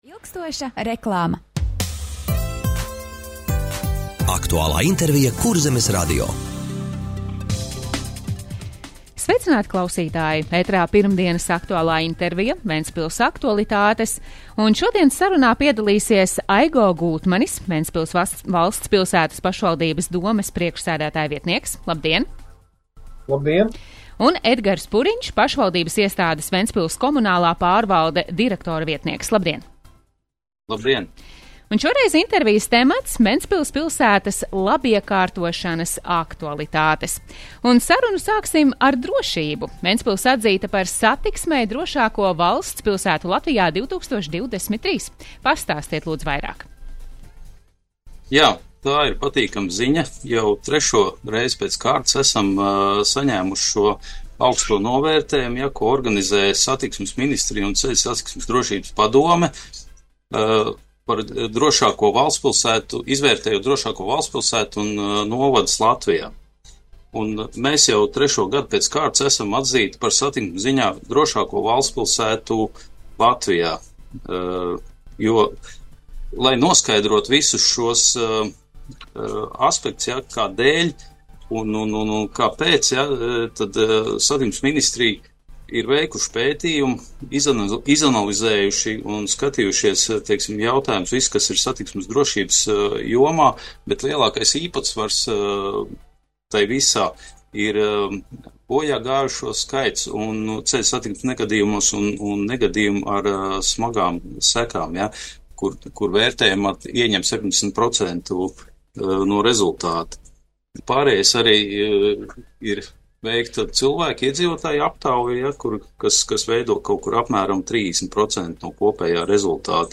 Radio saruna Ventspils pilsētas labiekārtošanas aktualitātes - Ventspils